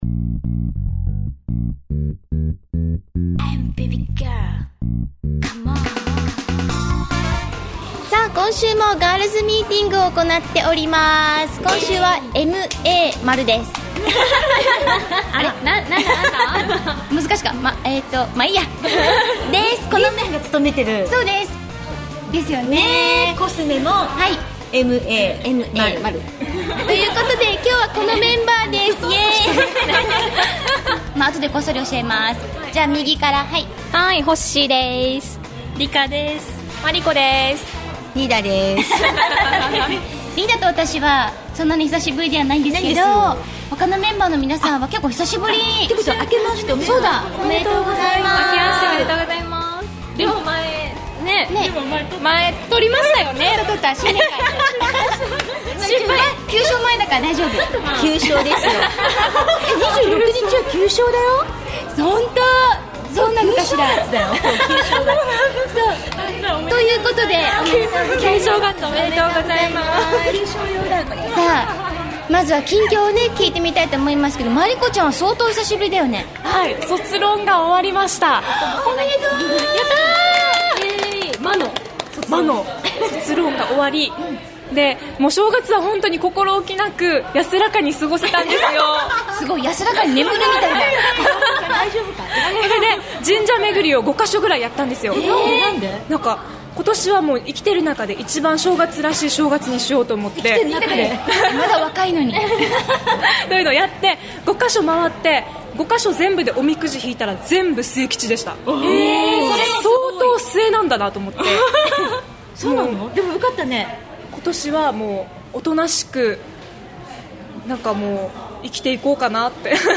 さて今週のガールズは何だかかなり盛り上がっています それもそのはず。。。